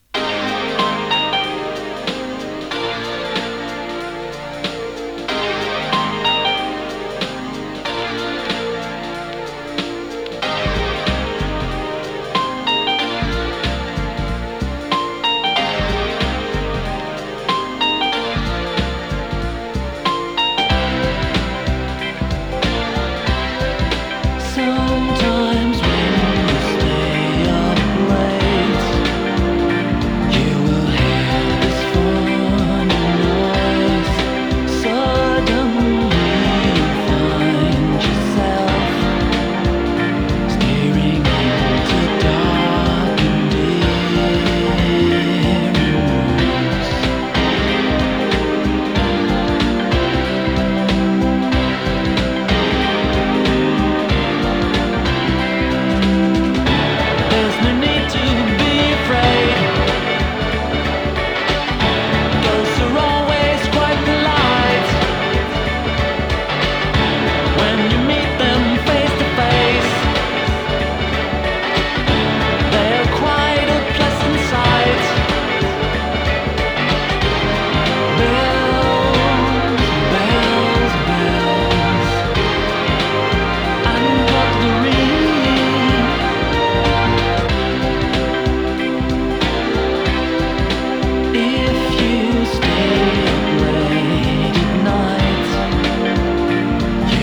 シンセポップ
心地よい浮遊感と温もりを感じさせるエレポップの傑作です！